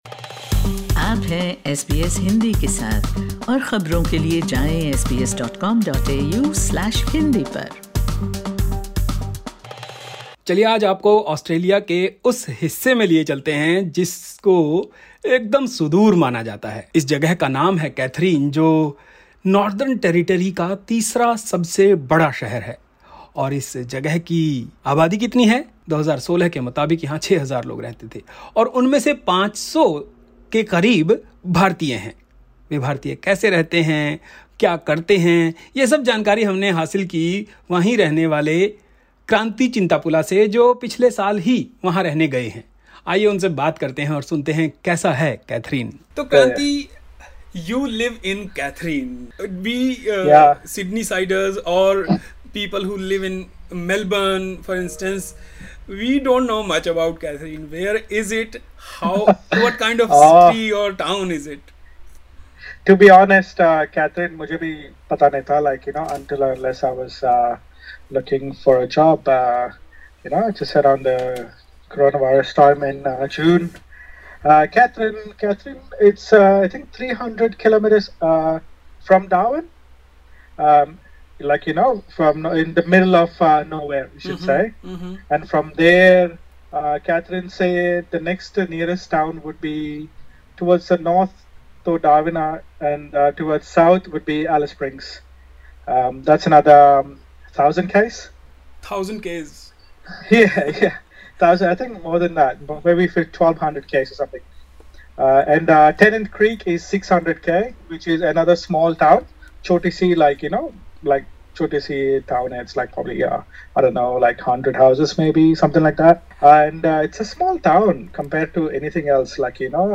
An Indo-Nepalese community of a few hundred people is thriving in this little town. Listen to the chat